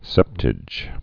(sĕptĭj)